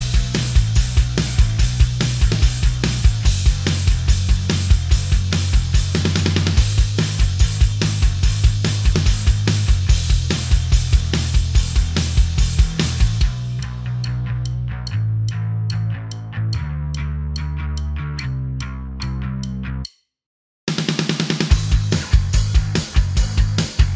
Minus All Guitars Indie / Alternative 3:07 Buy £1.50